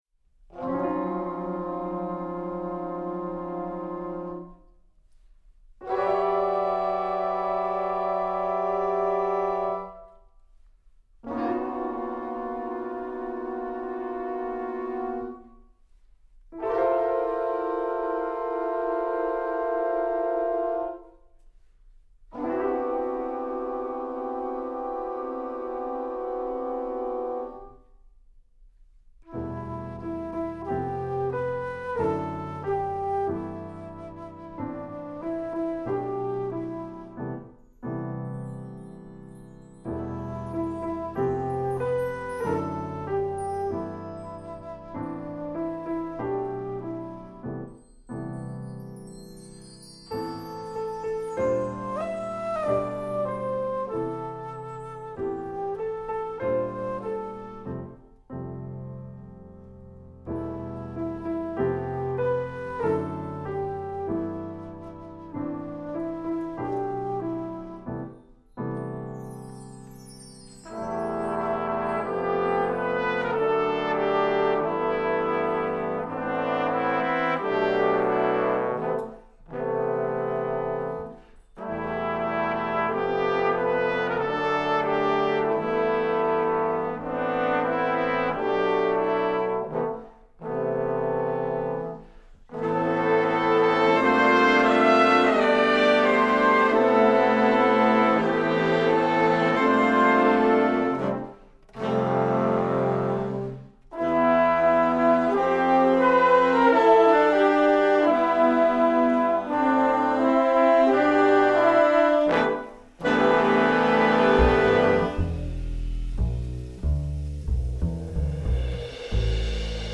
Doubles: alto flute, (3) clarinets, bass clarinet
Solos: alto flute